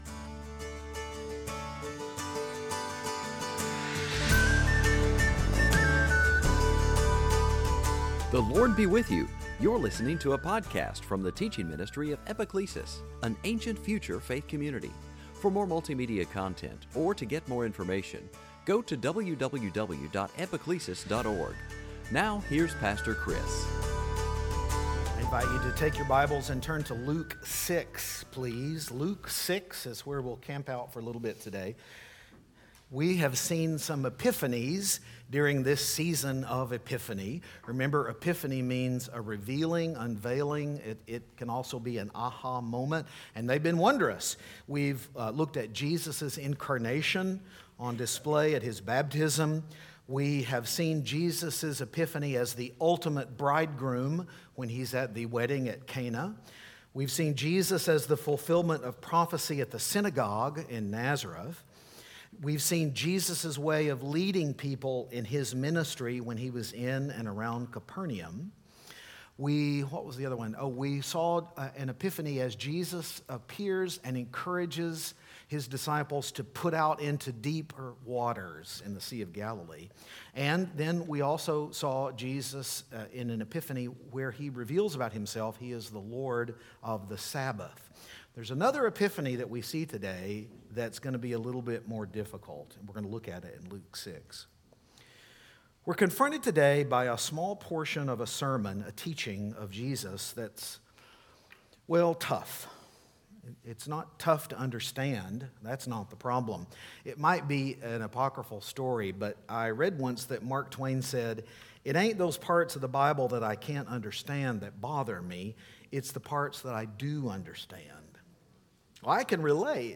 A tough teaching of Jesus that's not hard to understand but difficult to put into practice spurs conversation about how we love our enemies.